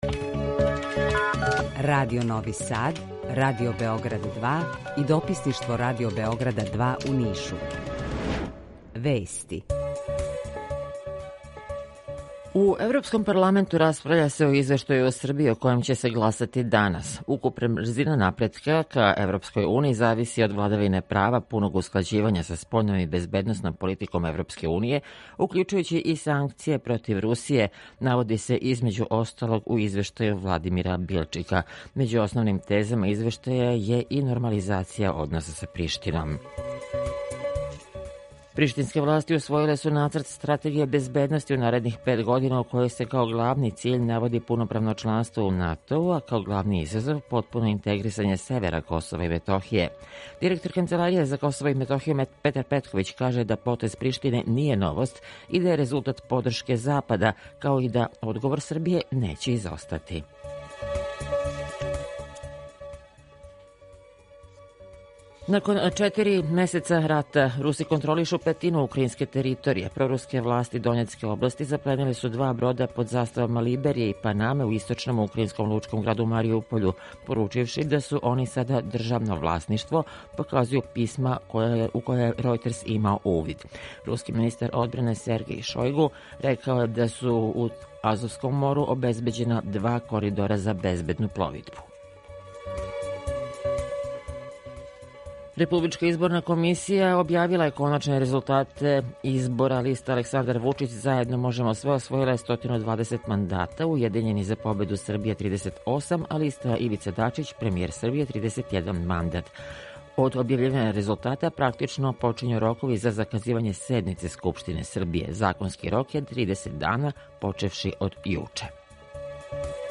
Извештај са Косова и Метохије
У два сата, ту је и добра музика, другачија у односу на остале радио-станице.